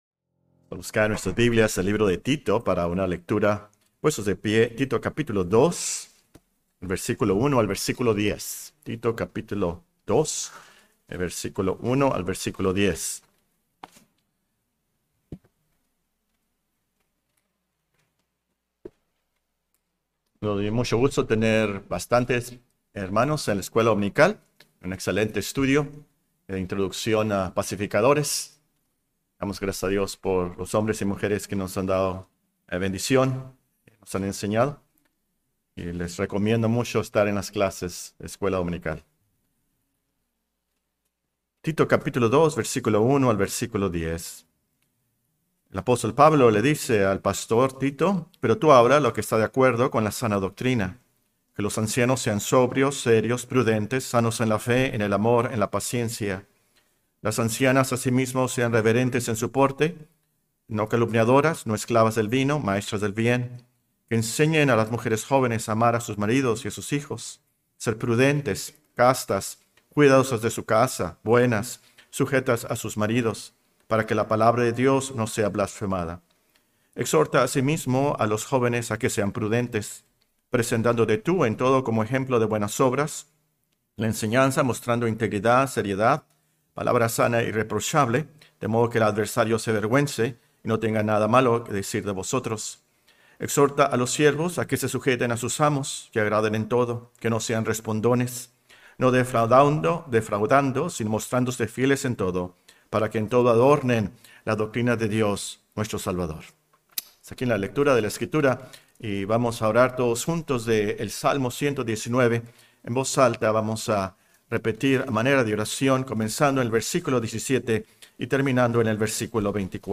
Serie de sermones Matrimonios Sanos